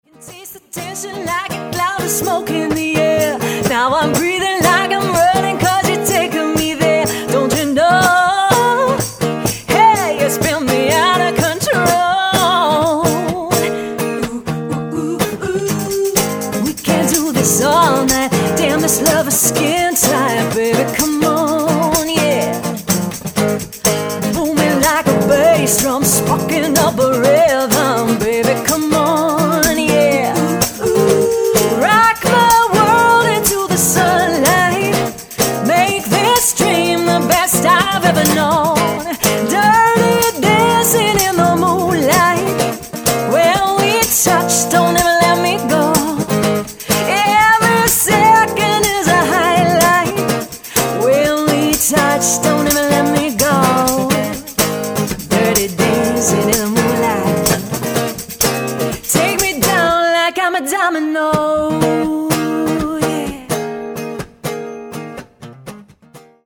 Live Demo’s!
guitar